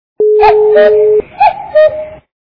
Кукушка в лесу - Ку-ку Звук Звуки Зозуля в лісі - Ку-ку
» Звуки » Природа животные » Кукушка в лесу - Ку-ку
При прослушивании Кукушка в лесу - Ку-ку качество понижено и присутствуют гудки.